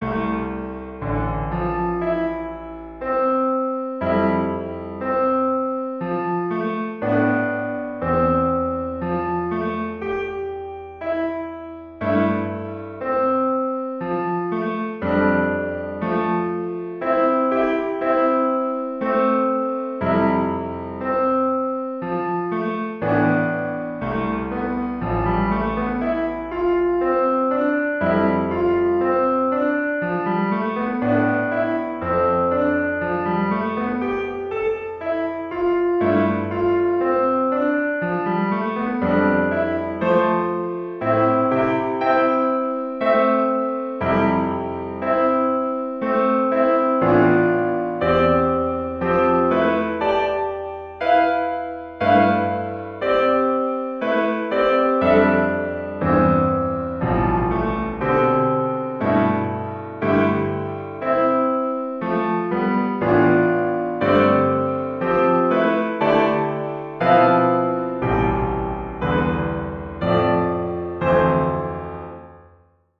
(Ballade en la mineur)